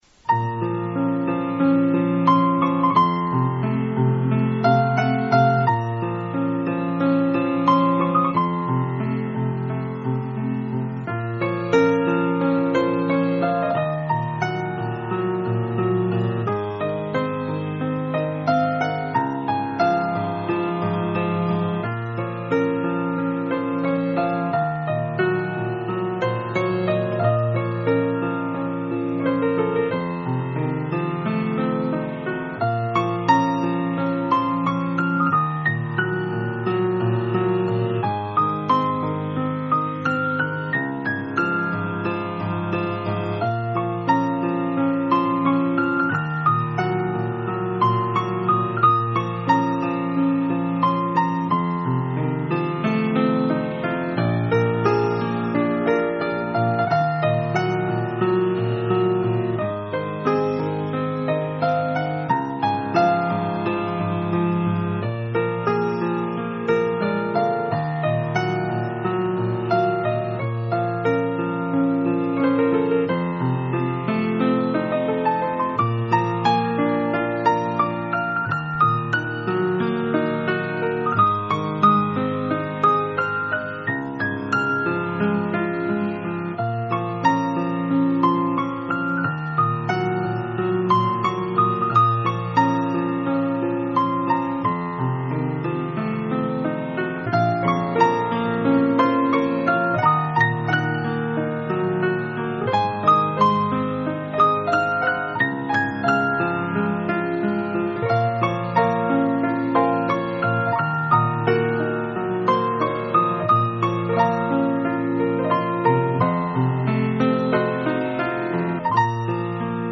Эрон мусиқаси